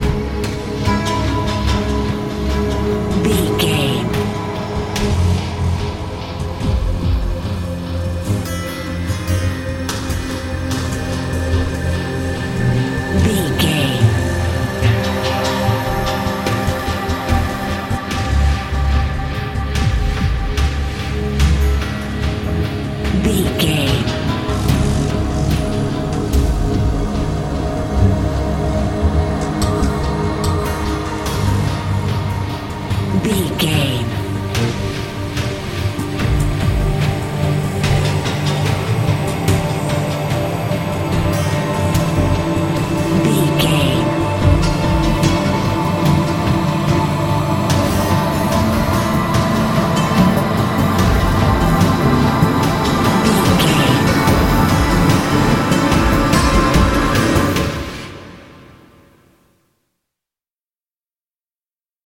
Thriller
Aeolian/Minor
strings
drums
cello
violin
percussion